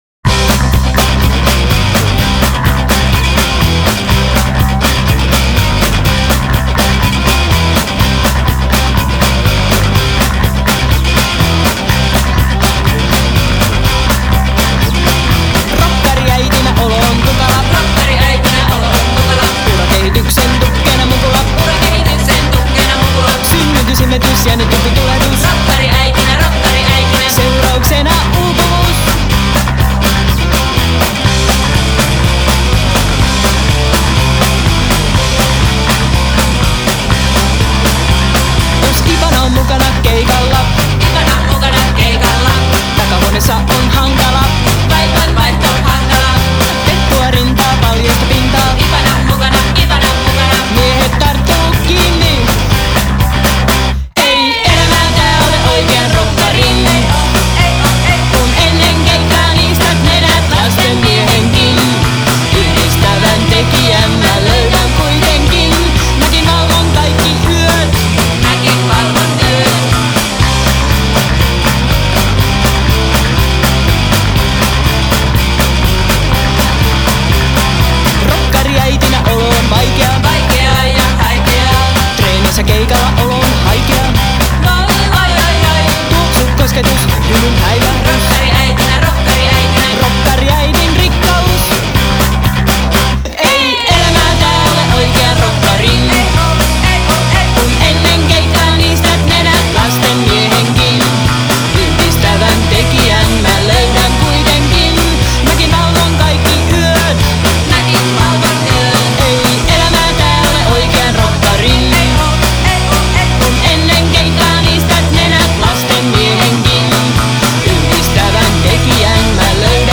kitara, taustalaulut
basso, taustalaulut
rummut, taustalaulut
treenikämpällä ja Riku-studiossa